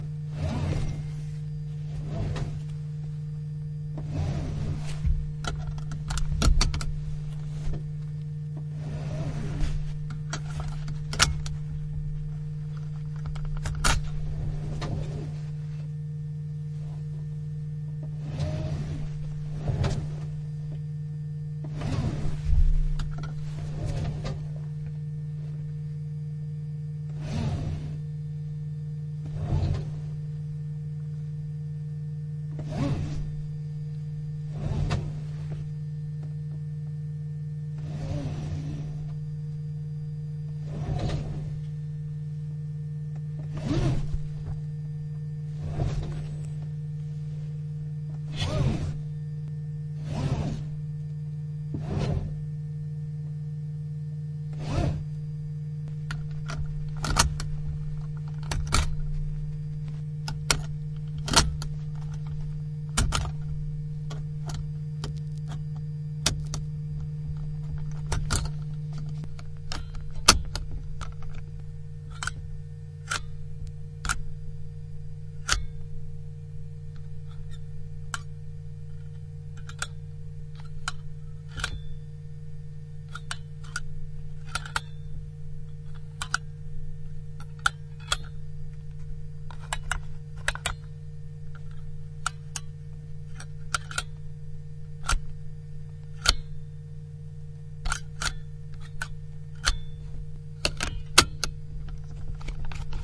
描述：安全带。系上，锁上，解锁......
Tag: 皮带 汽车 驾驶舱 座椅